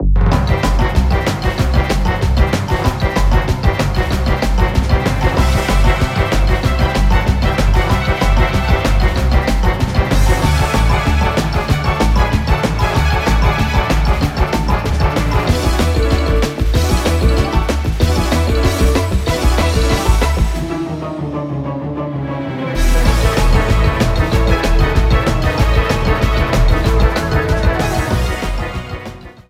clipped to 30 seconds and applied fade-out